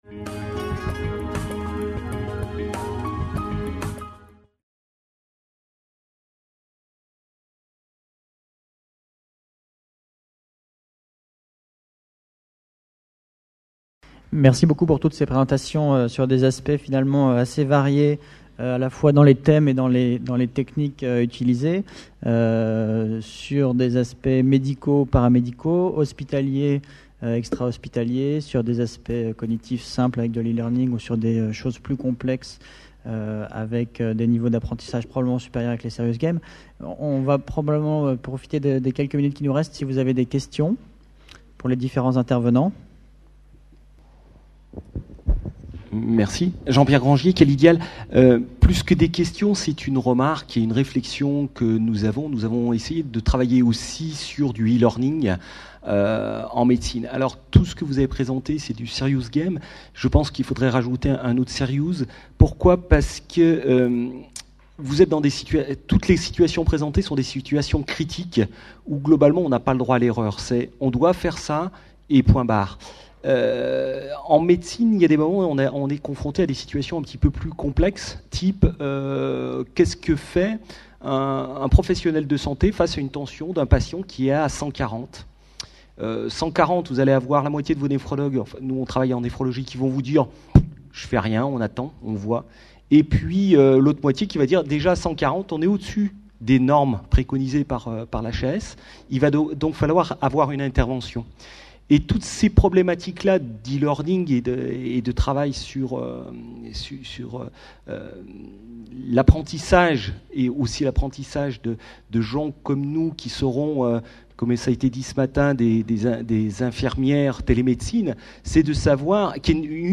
Conférence enregistrée lors du congrès international FORMATIC PARIS 2011. Atelier TIC et pratiques innovantes au service de la formation des professionnels de la santé.